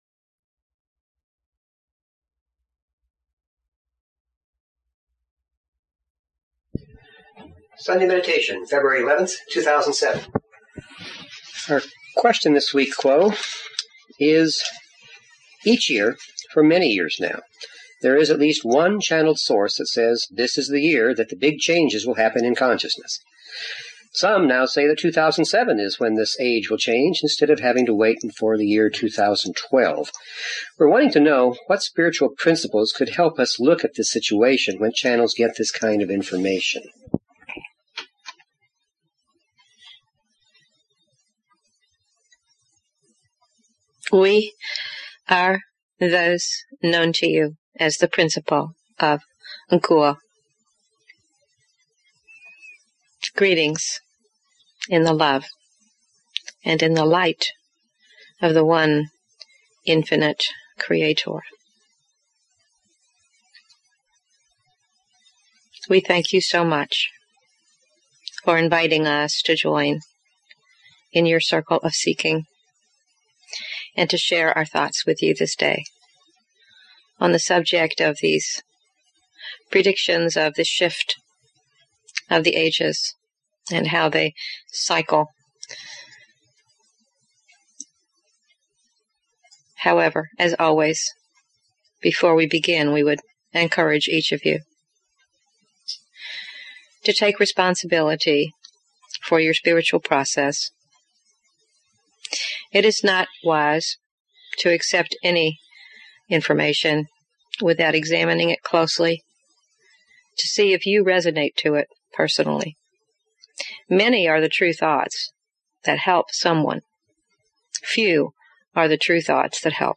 /llresearchquocommunications#archives Paranormal Philosophy Physics & Metaphysics Spiritual Medium & Channeling 0 Following Login to follow this talk show LL Research Quo Communications